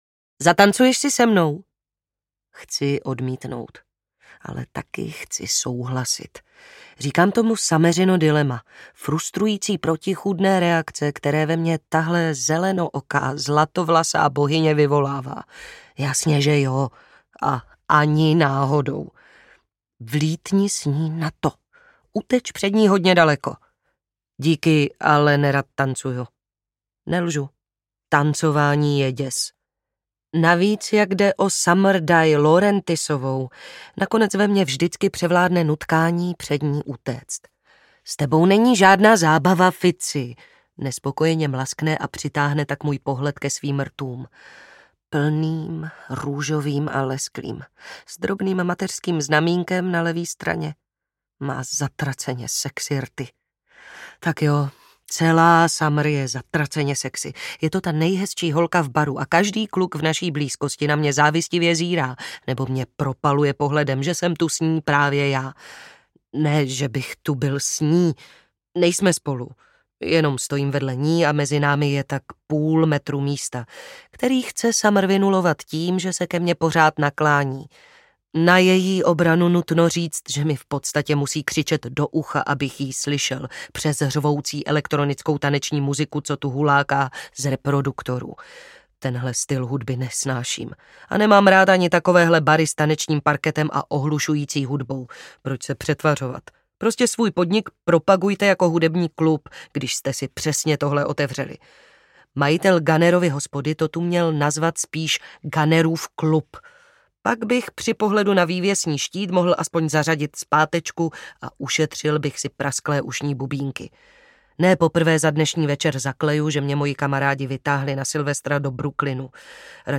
Lov audiokniha
Ukázka z knihy
Vyrobilo studio Soundguru.